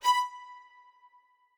strings1_16.ogg